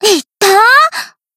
BA_V_Mika_Battle_Damage_2.ogg